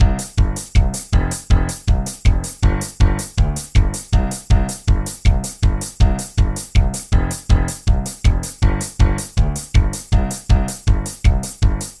Music Loops